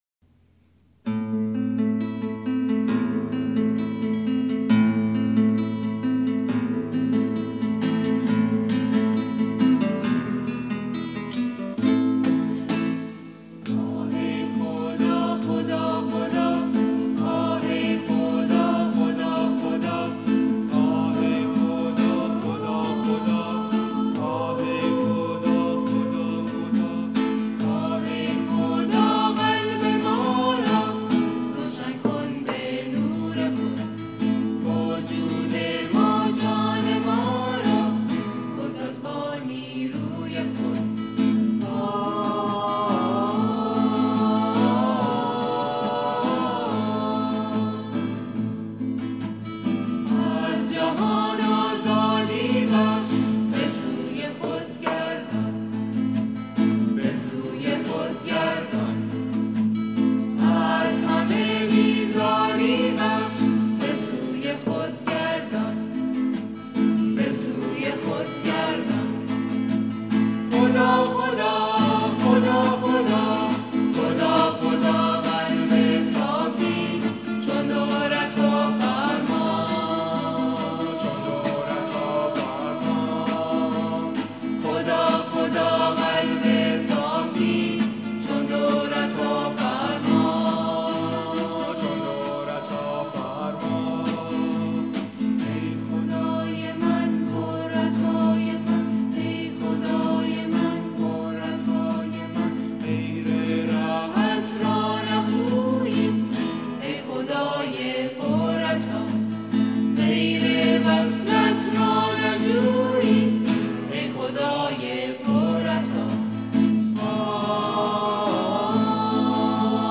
سرود - شماره 1 | تعالیم و عقاید آئین بهائی